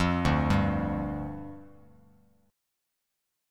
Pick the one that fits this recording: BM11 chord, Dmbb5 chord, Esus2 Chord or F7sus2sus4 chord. Dmbb5 chord